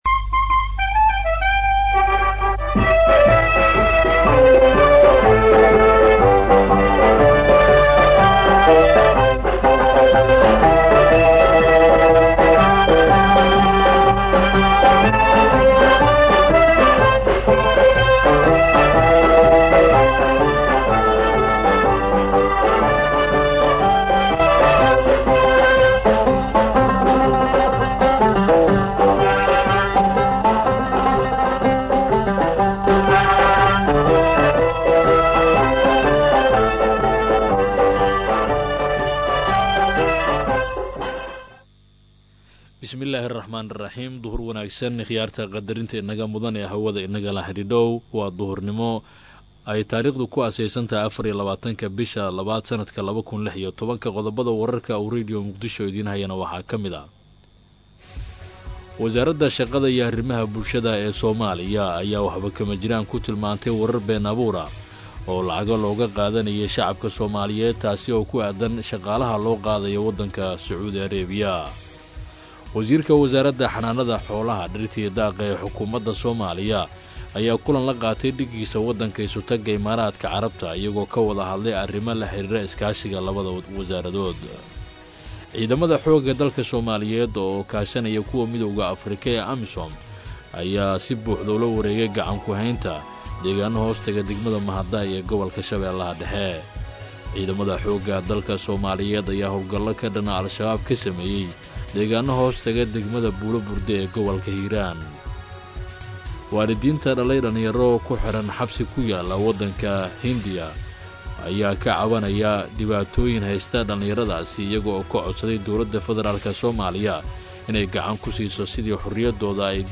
Dhageyso Warka Duhur ee Radio Muqdisho